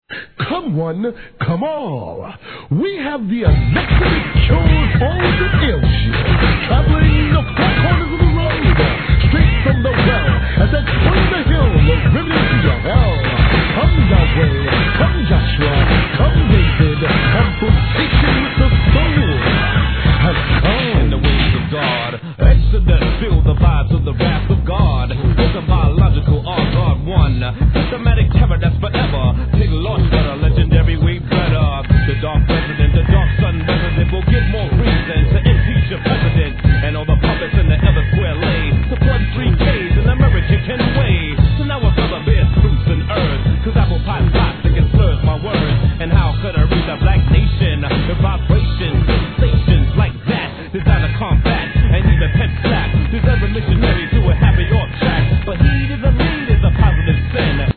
HIP HOP/R&B